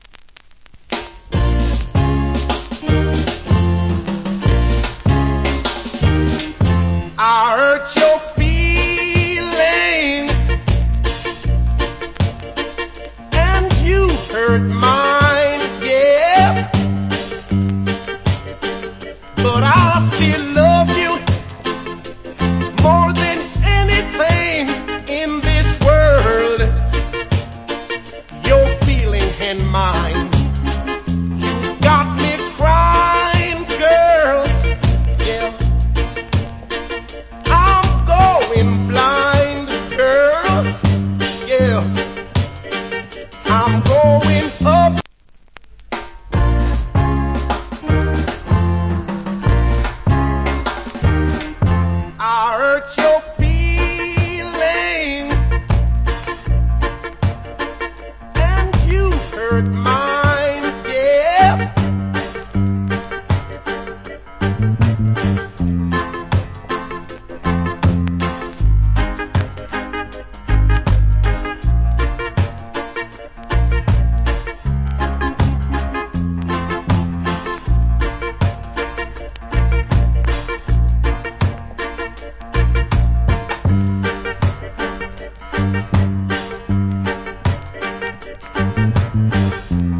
Killer Reggae Vocal.